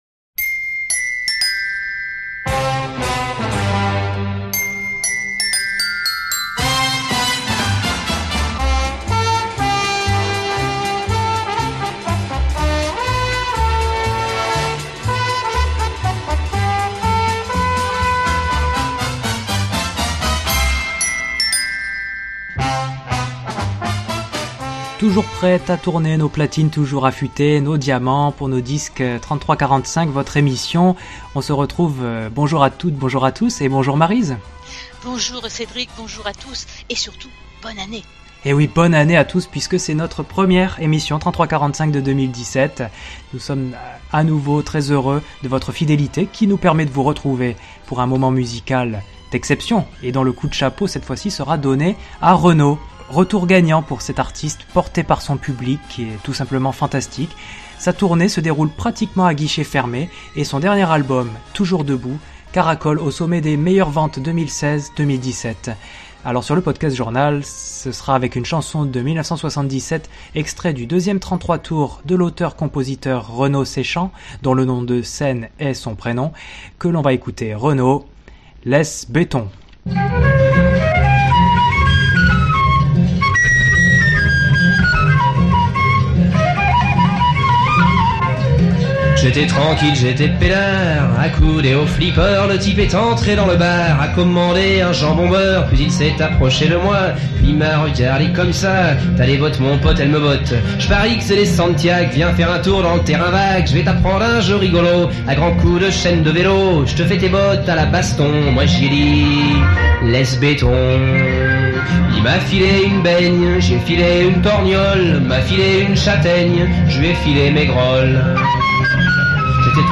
Le Podcast Journal vous propose cette émission musicale dédiée aux années vinyles